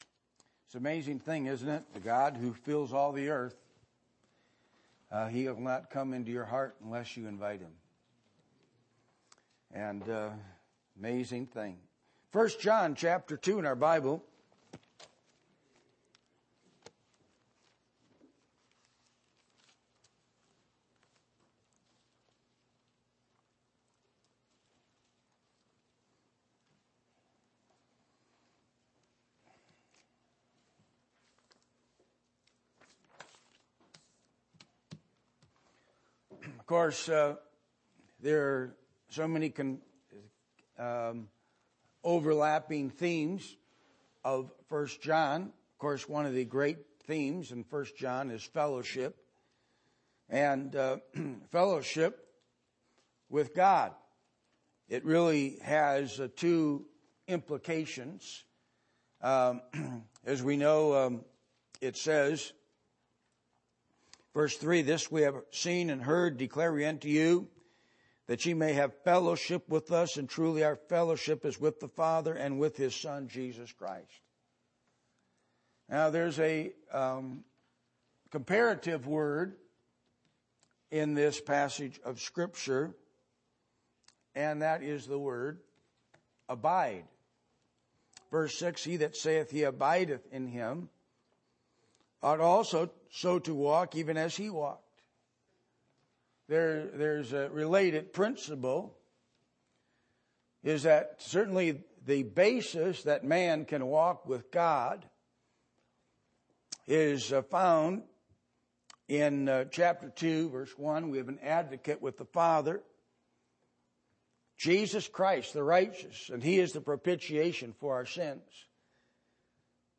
Passage: 1 John 2:1-29 Service Type: Sunday Morning %todo_render% « How is your Sight?